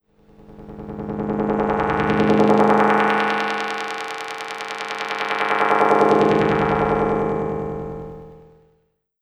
Lo Fi Event No 1.wav